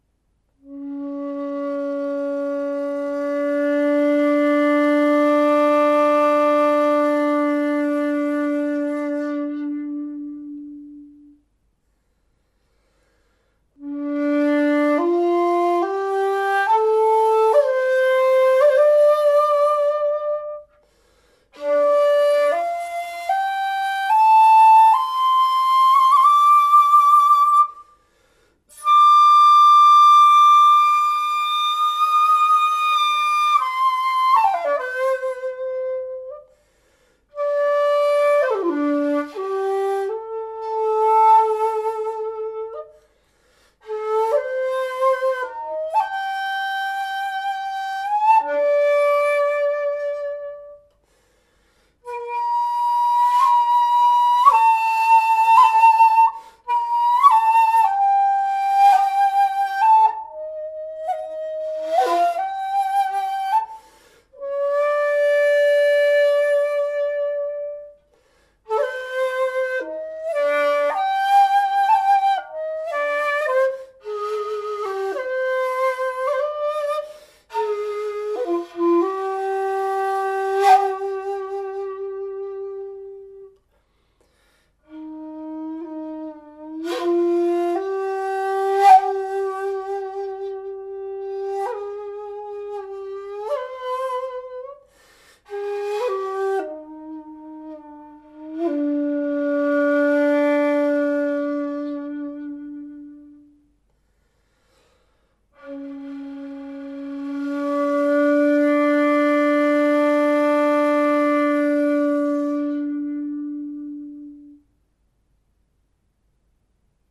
Chikudo Ji-ari shakuhachi 1.8 in D | Atelier Chikudo
To sell : Ji-ari shakuhachi from Japanese Madake.